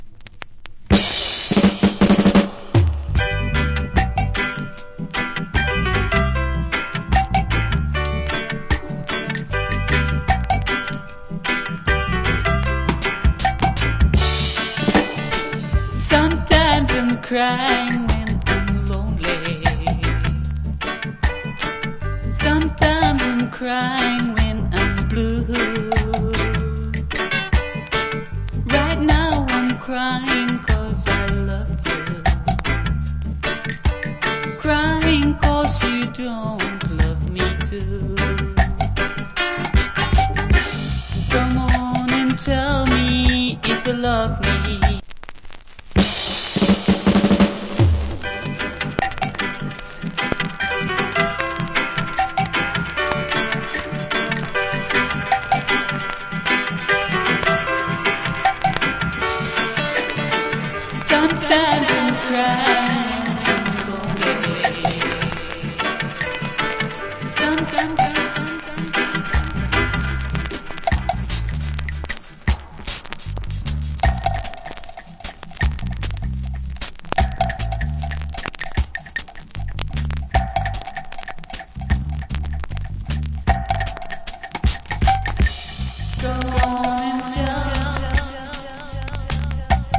Good Female Reggae Vocal.